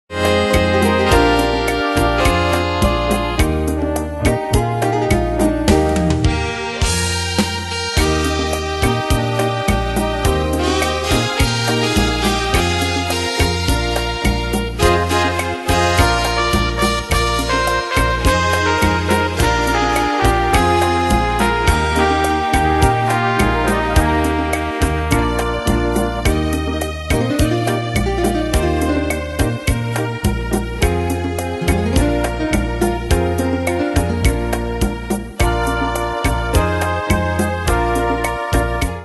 Danse/Dance: Rhumba Cat Id.
Pro Backing Tracks